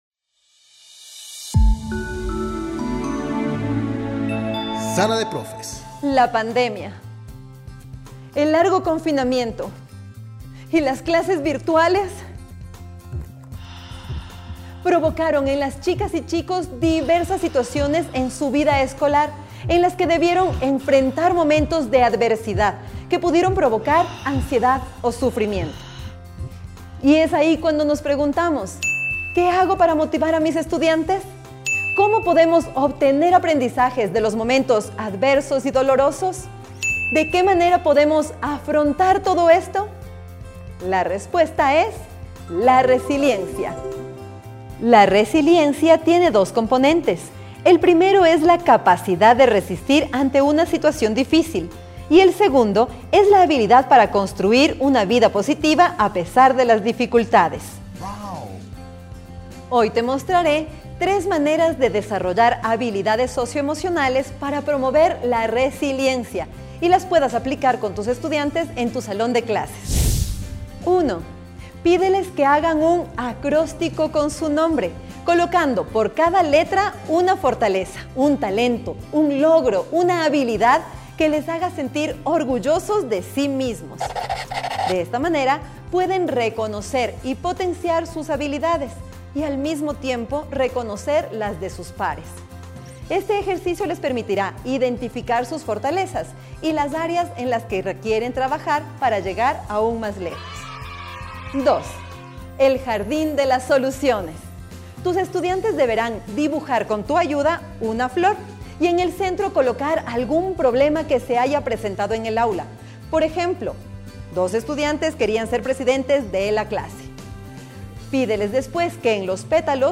Descripción: La profesora presenta tres maneras para estimular la creatividad de niñas y niños, basadas en la preparación del ambiente, el juego y los momentos de relajación y atención plena.